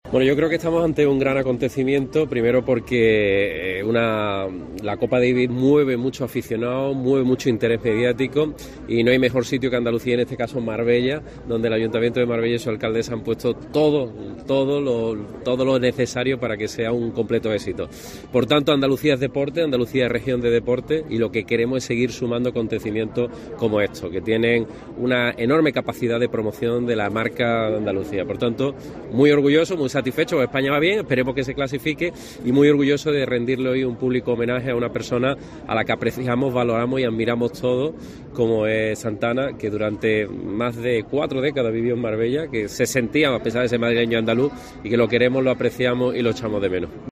"Andalucía es región de deporte y lo que queremos es seguir sumando acontecimientos como este", ha destacado Moreno en una atención medios.